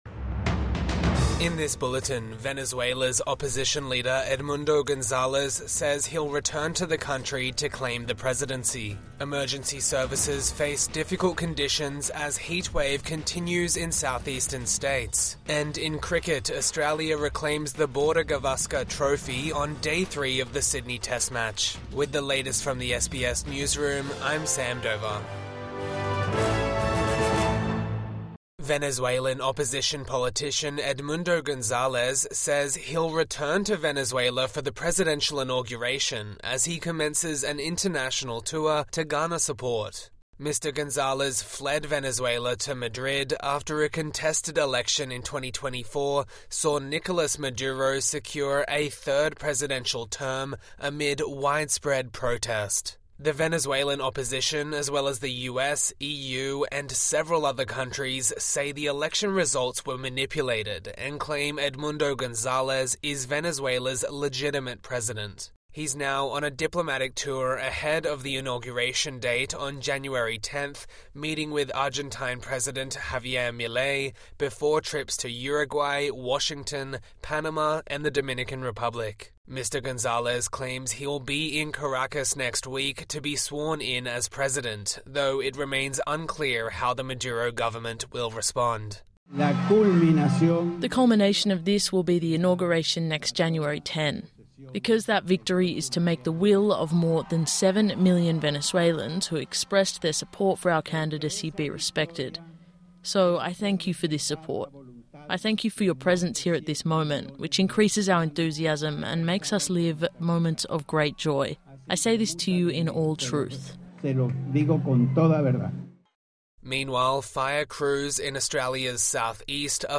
Evening News Bulletin 5 January 2025